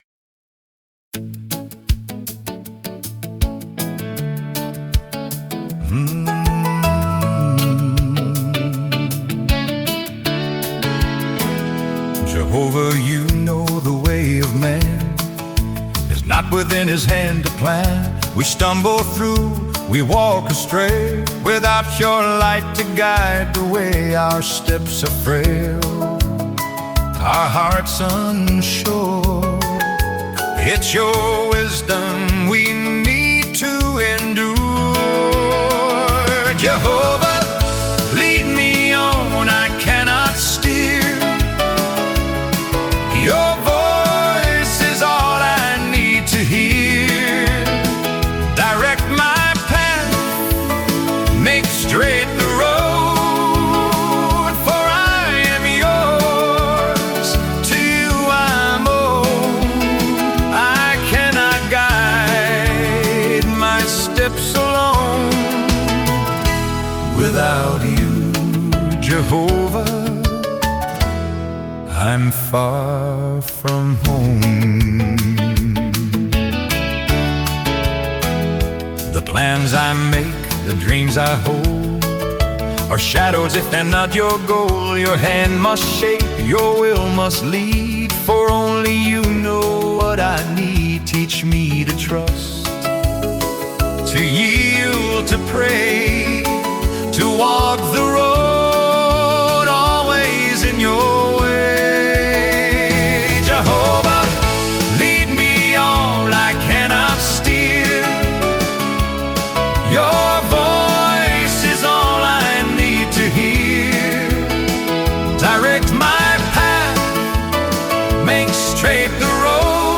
Encouraging and emotional Songs